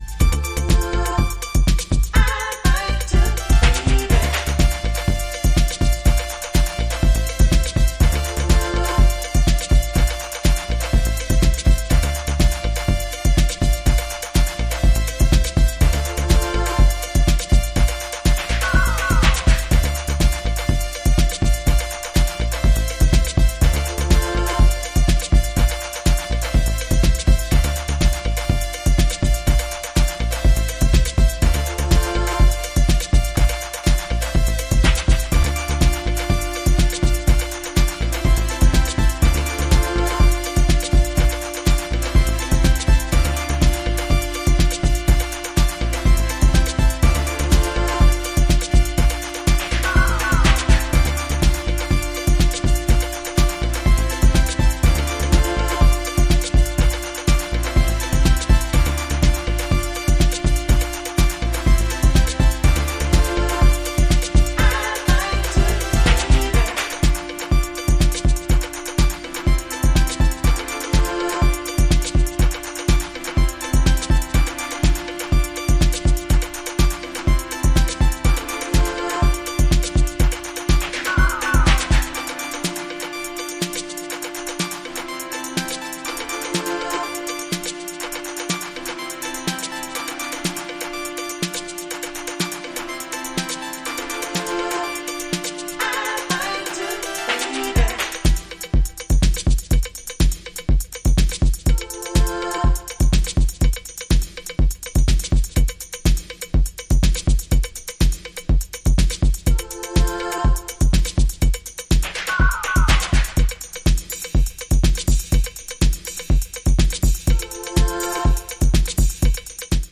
New Release Deep House Disco House